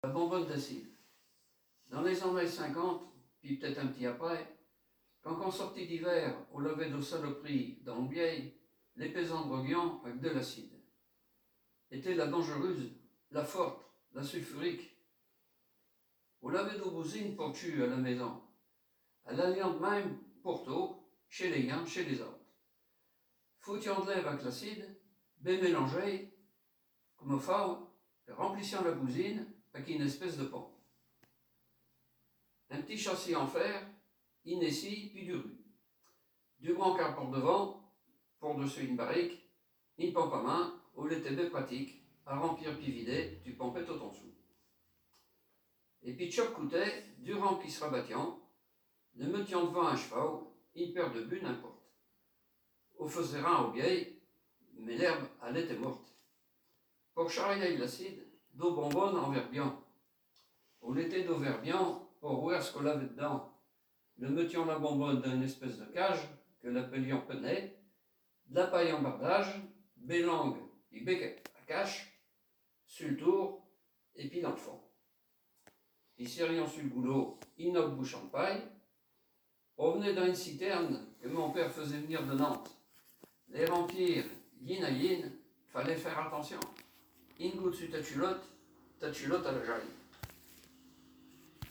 Genre poésie
Poésies en patois